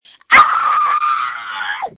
• When you call, we record you making sounds. Hopefully screaming.
• This website is an archive of the recordings we received from hundreds of thousands of callers.
You might be unhappy, terrified, frustrated, or elated.